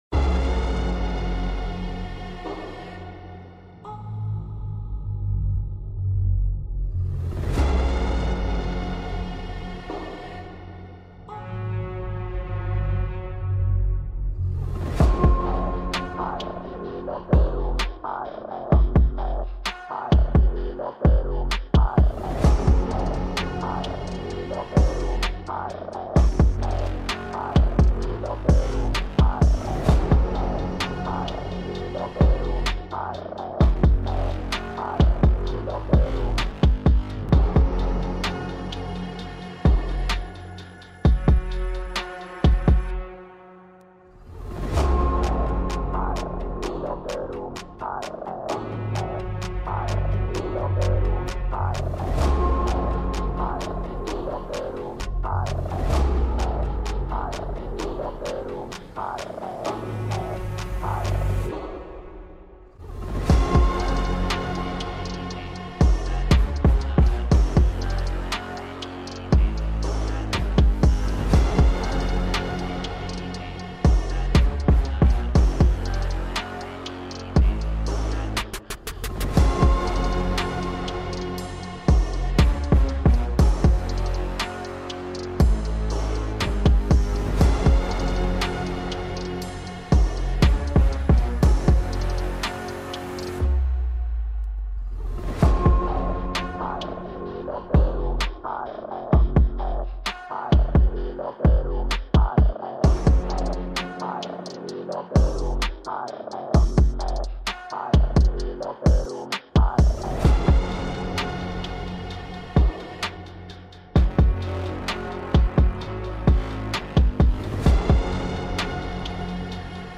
Ich versuche jede Silbe relativ gleichmäßig auszusprechen.